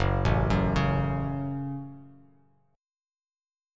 Gbm Chord
Listen to Gbm strummed